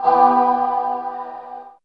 64 GUIT 4 -R.wav